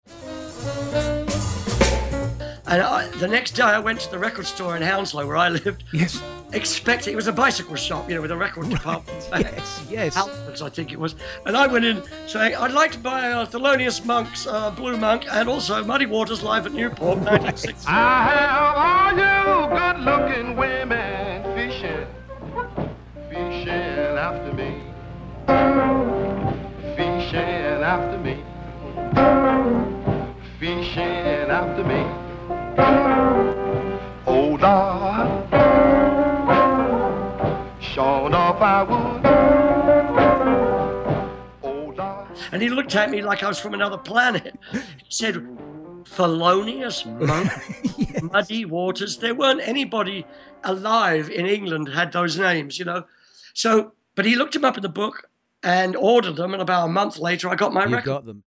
Ian McLagan, musician